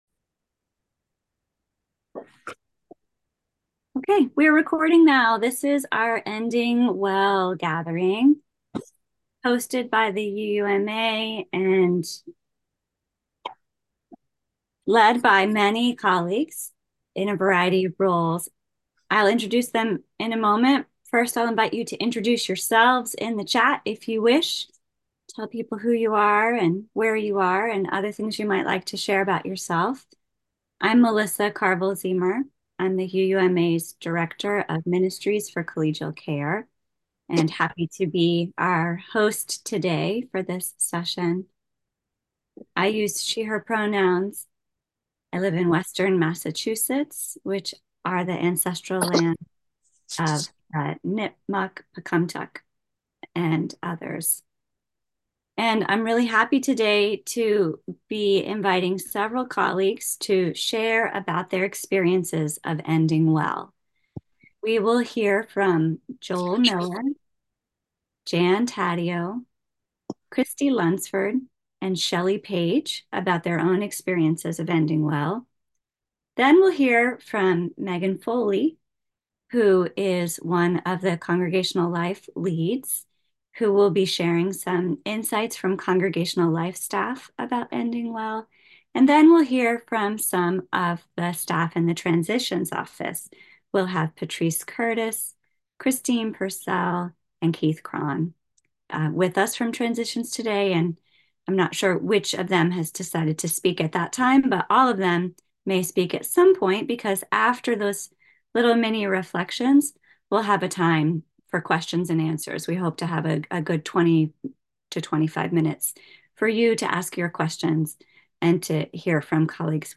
This week we share a rebroadcast last week’s webinar “Ending Well”